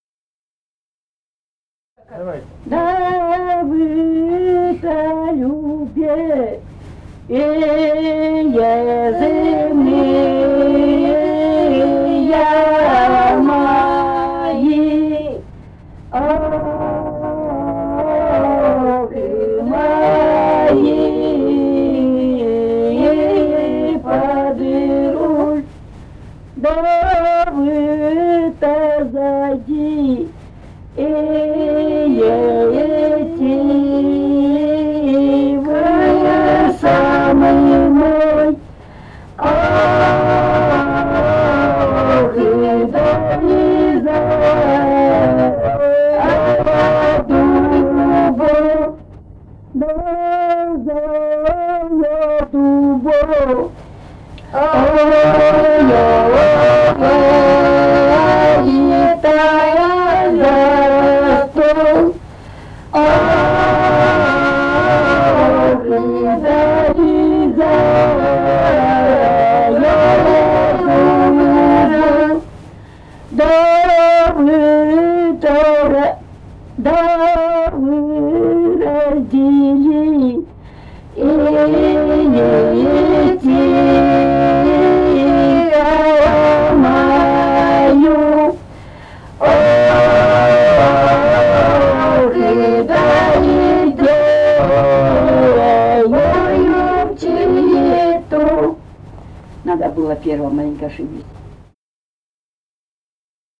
Этномузыкологические исследования и полевые материалы
Алтайский край, с. Тигирек Краснощёковского района, 1967 г. И1019-03